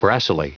Prononciation du mot brassily en anglais (fichier audio)
Prononciation du mot : brassily